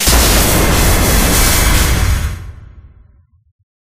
Magic7.ogg